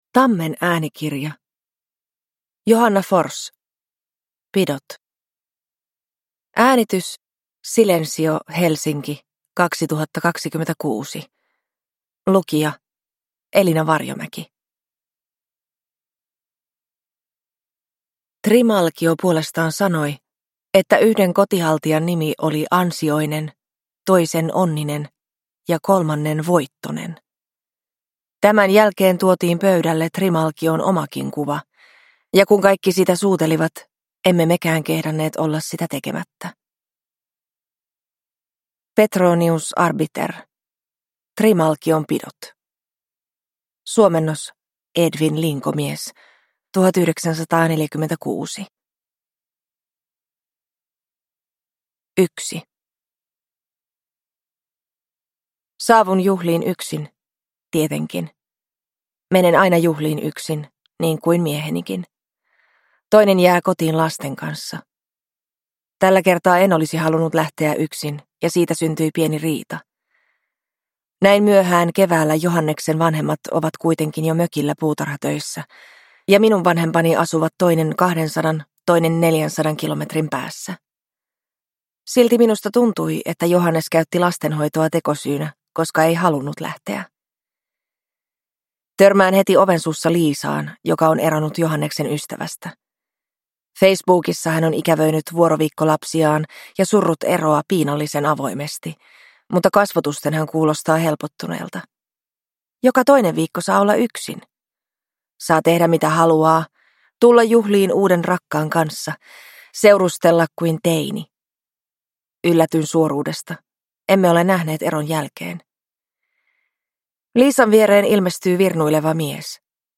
Pidot (ljudbok) av Johanna Forss